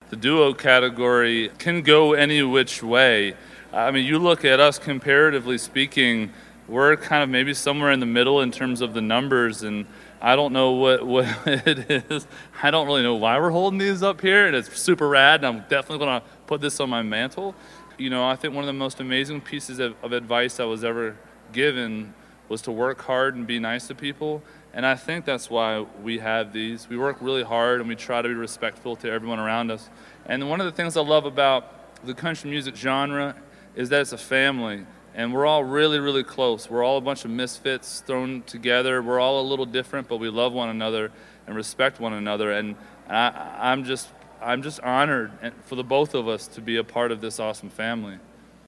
Audio / From backstage at last year's CMA Awards, Brothers Osborne are giddy after winning the Duo of the Year Award.